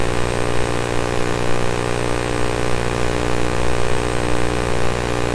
静电
描述：音频馈送的标准化背景噪声。
Tag: 数码 电子 噪声 静电